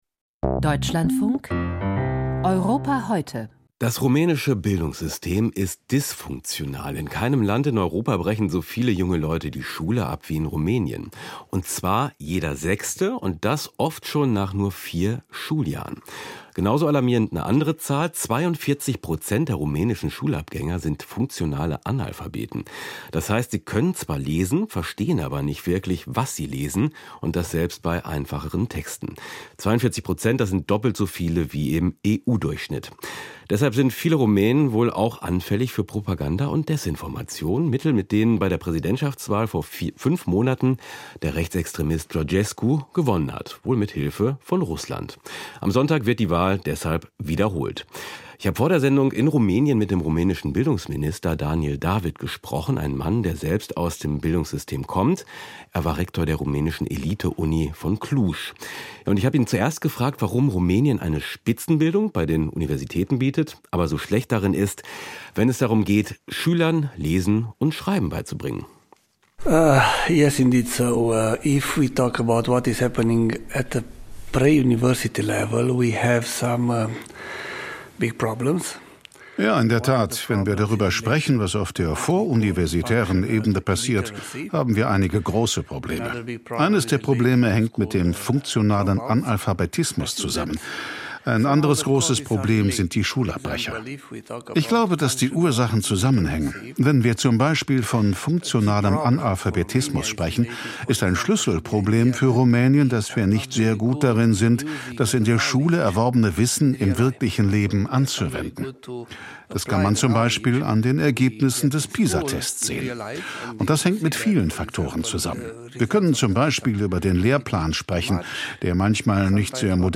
Dysfunktionales Schulsystem: Interview Daniel David, Bildungsminister Rumäniens